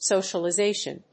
音節so・cial・i・za・tion 発音記号・読み方
/sòʊʃəlɪzéɪʃən(米国英語), s`əʊʃəlɑɪzɪʃən(英国英語)/